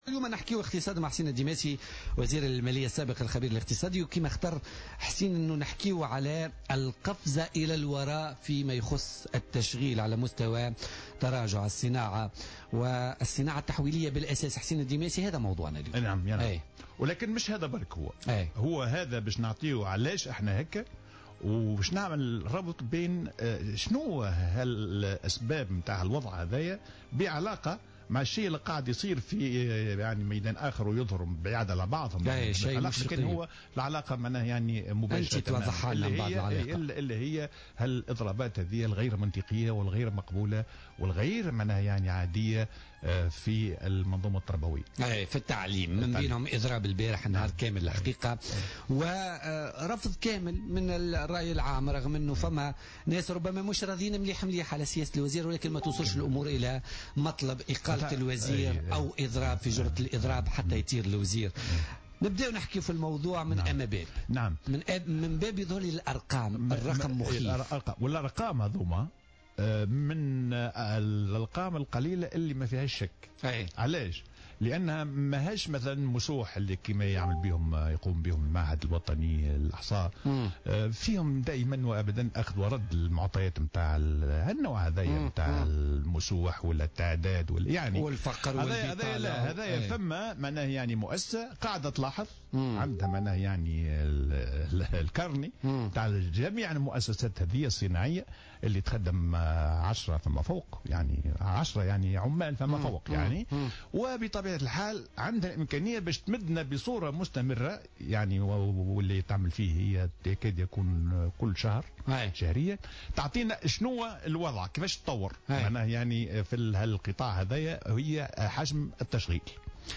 قال الخبير الاقتصادي حسين الديماسي خلال استضافته اليوم الجمعة في برنامج "بوليتكا" إن تونس فقدت 20 ألف موطن شغل في الصناعات التحويلية خلال سنة 2016، حوالي 75 بالمائة منها في قطاع الملابس والأحذية ثم تليها الصناعات الكهربائية والميكانيكية.